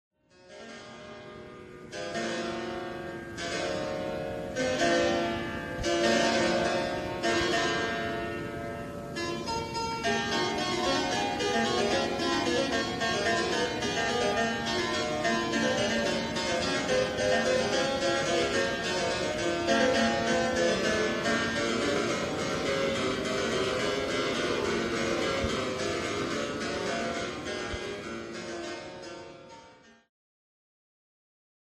ジャンル チェンバロ
古楽
撥弦楽器系